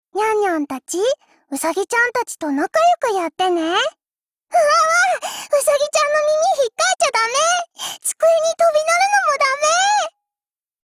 贡献 ） 协议：Copyright，人物： 碧蓝航线:阿蒂利奥·雷戈洛语音 您不可以覆盖此文件。